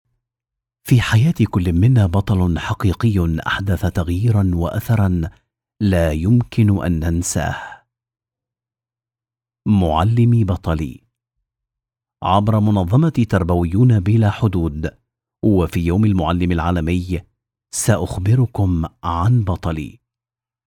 Adulto joven
Mediana edad